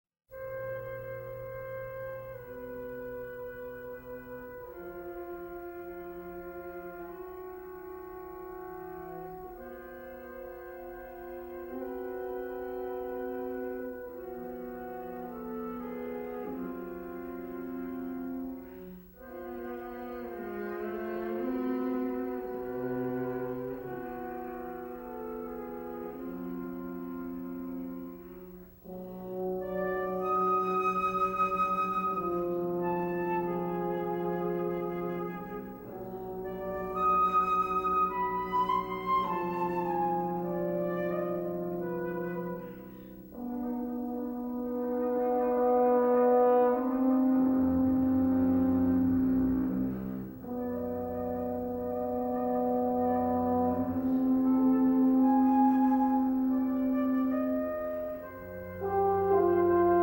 horn